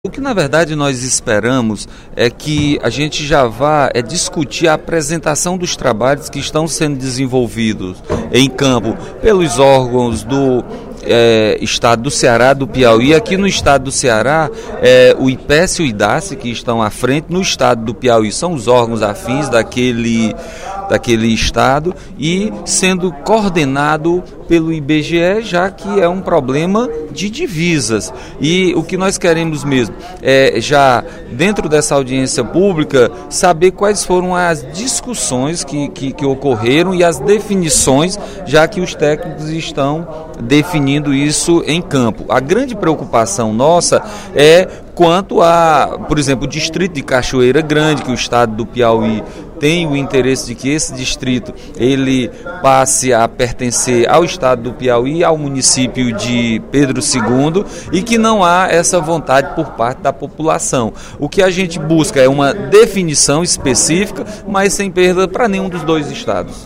O deputado Nenen Coelho (PSD) anunciou, em pronunciamento no primeiro expediente desta quinta-feira (28/02), que entrou com requerimento pedindo a realização de audiência pública para discutir as divisas do Ceará com o Piauí.
Em aparte, o deputado Roberto Mesquita (PV) disse que a questão das divisas é importante.